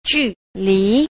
10. 距離 – jùlí – cự ly (khoảng cách)
ju_li.mp3